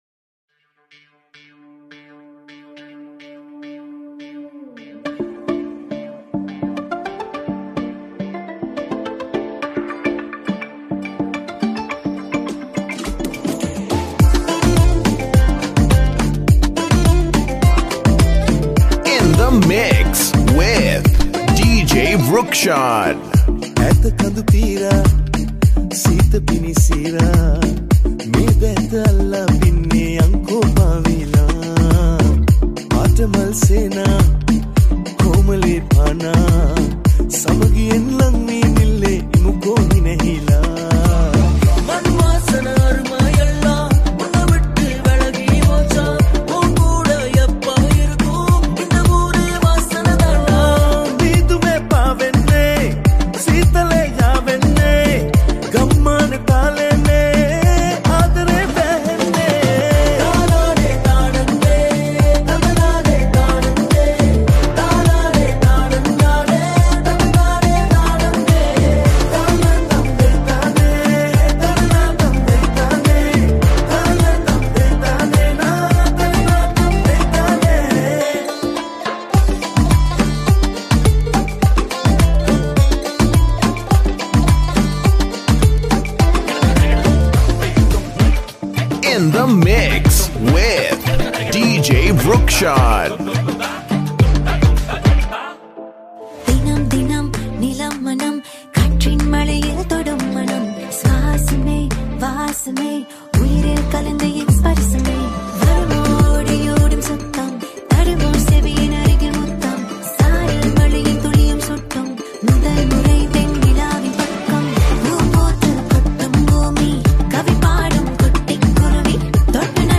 Remix
Genre - Club House